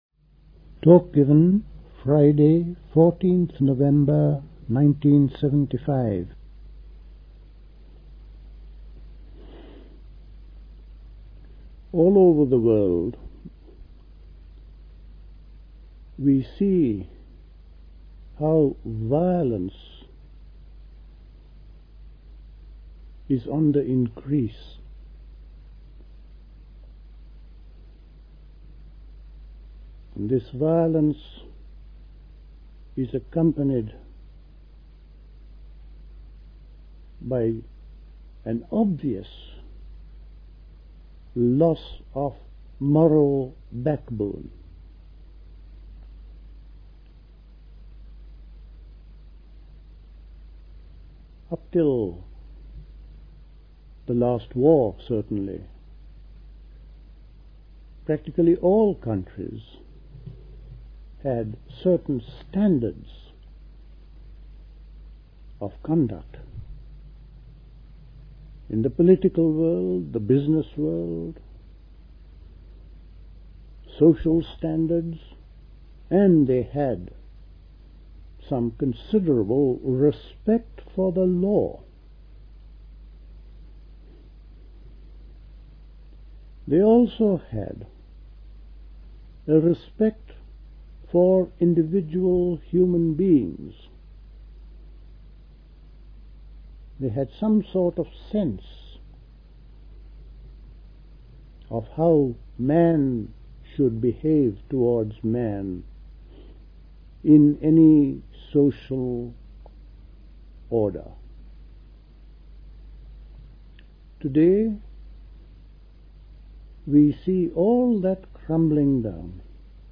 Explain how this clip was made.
Dilkusha, Forest Hill, London